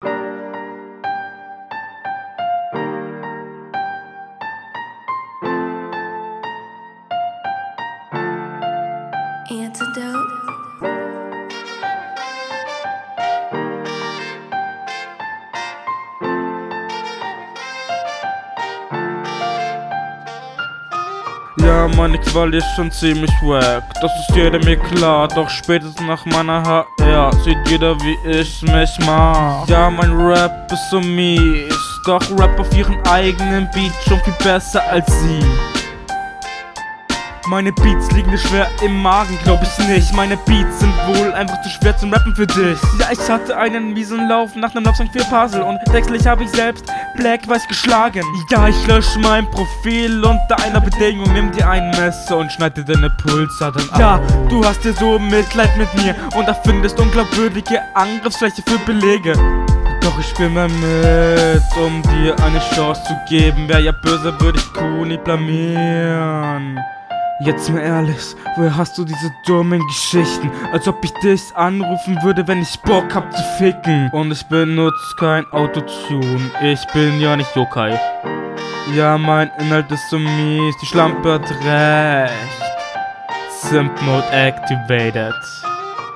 Finde ich besser als deine bisherigen Sachen und du bist echt nice auf dem Beat, …
Du klingst dreckig, was mir gefällt, jetzt noch …